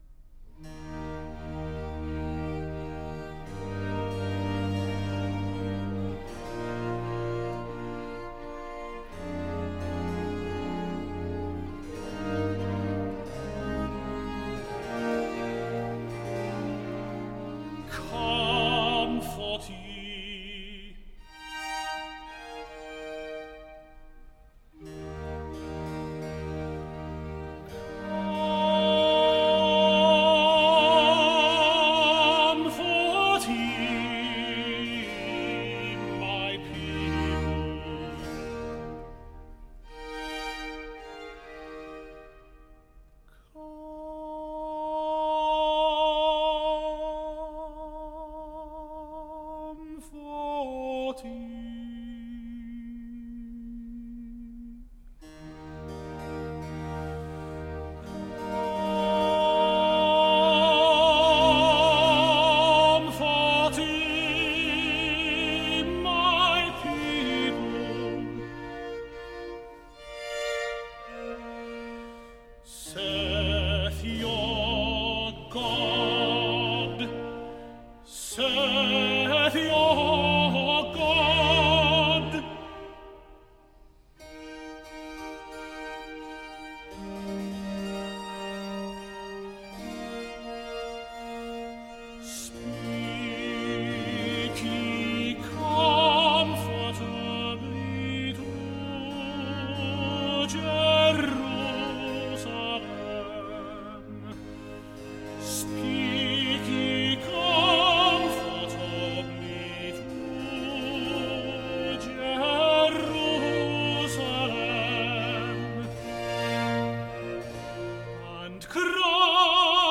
Oratorio
Group: Classical vocal
Handel - Messiah - 02 Recitative _ Comfort Ye!